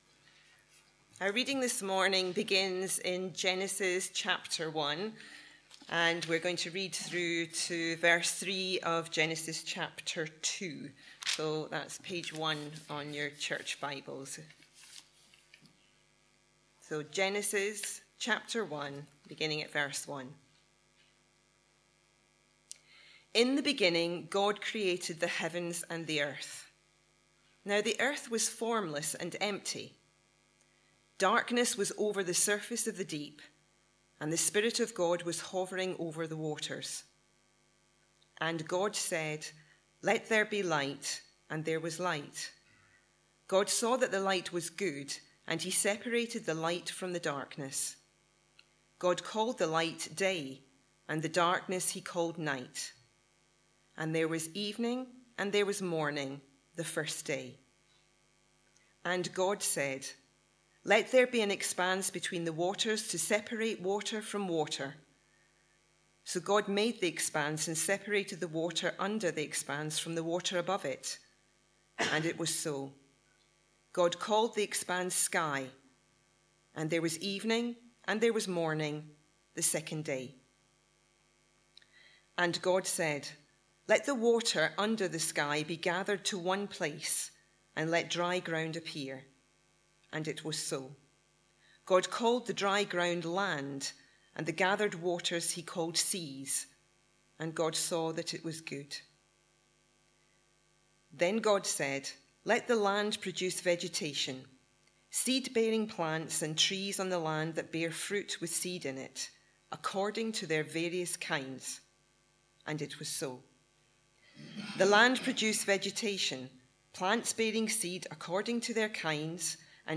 A sermon preached on 10th January, 2016, as part of our The God who makes himself known series.